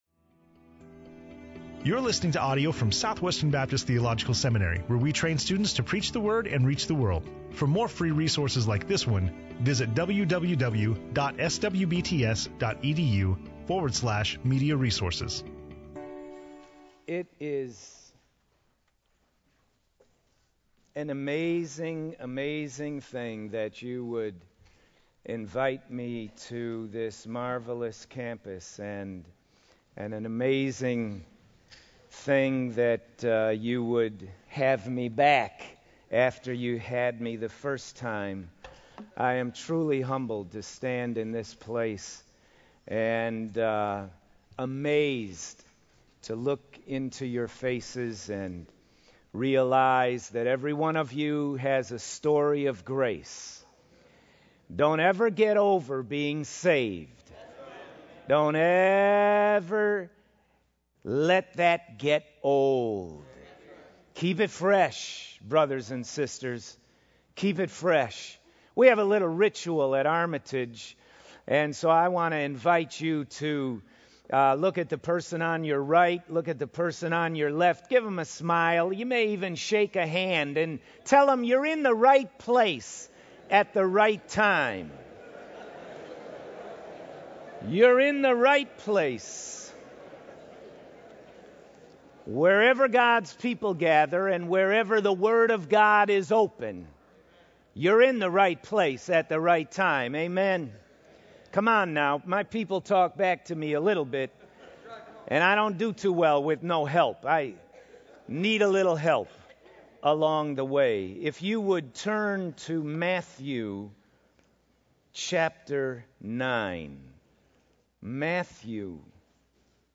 in SWBTS Chapel on Tuesday March 29, 2011